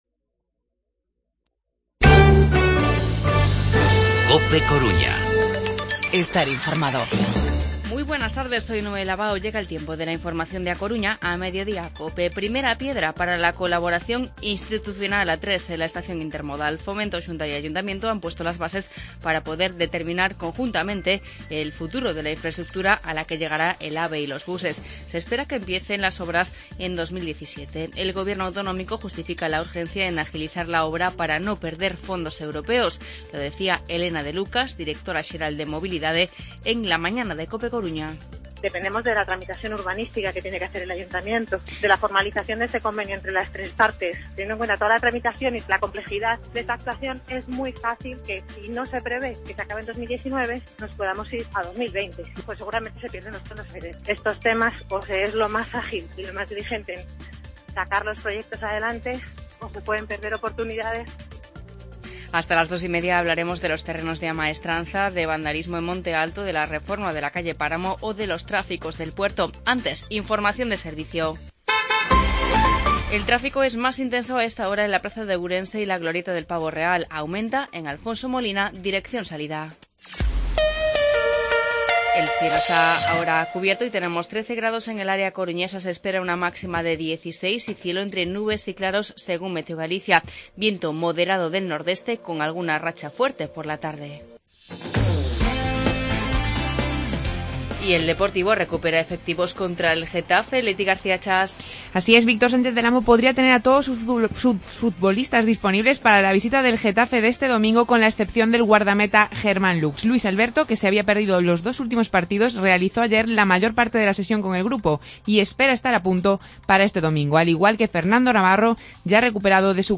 Informativo Mediodía COPE Coruña miércoles, 27 de abril de 2016